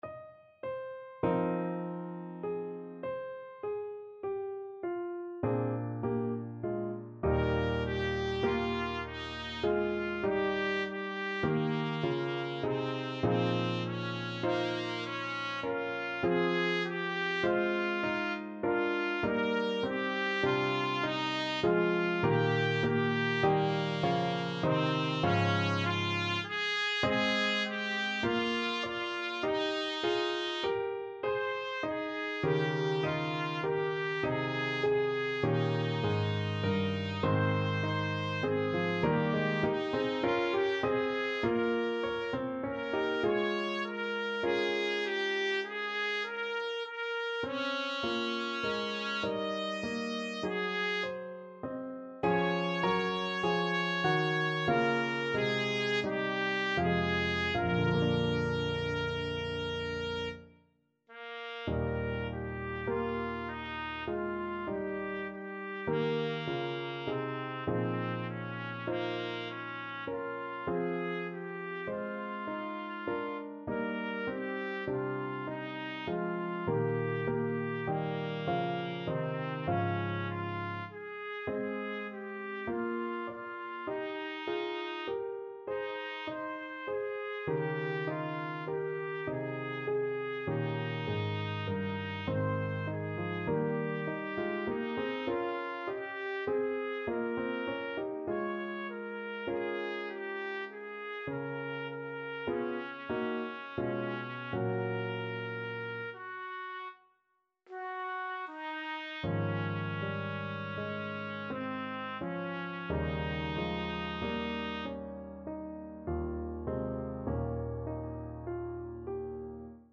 Trumpet version
5/4 (View more 5/4 Music)
Classical (View more Classical Trumpet Music)